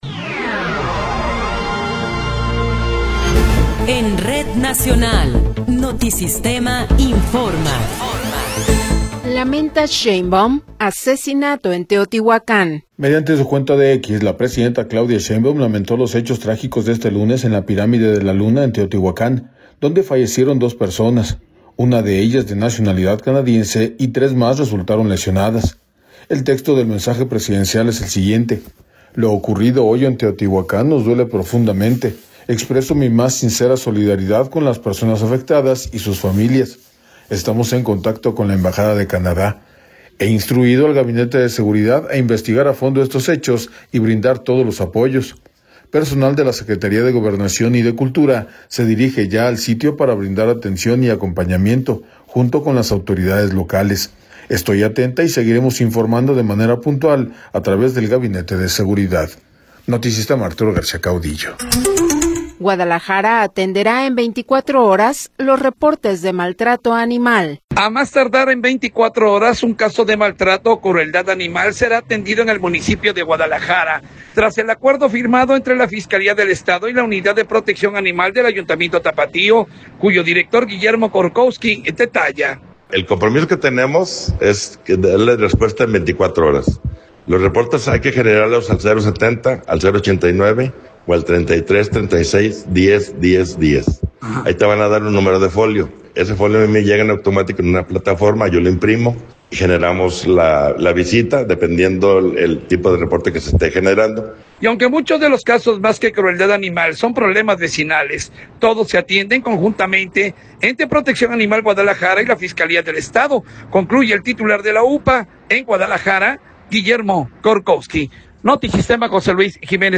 Noticiero 15 hrs. – 20 de Abril de 2026
Resumen informativo Notisistema, la mejor y más completa información cada hora en la hora.